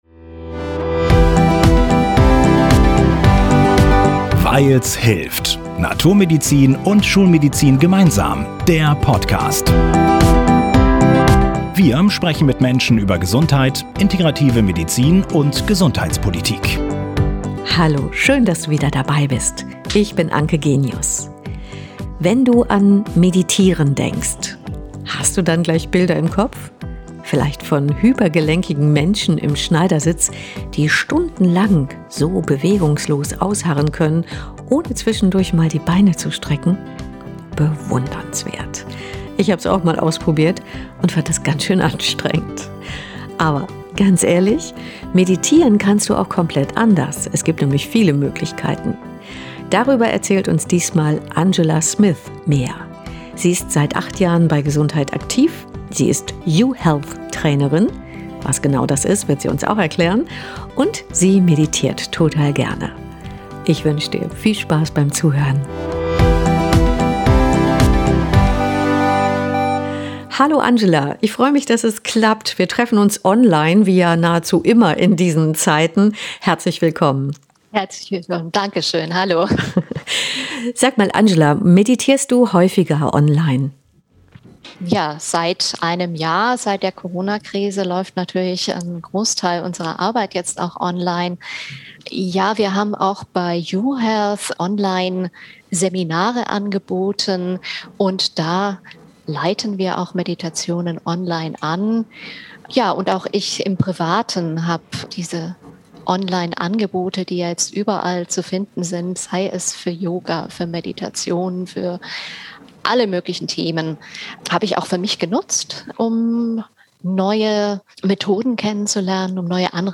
Mehr zu unserer Interviewpartnerin und weitere Informationen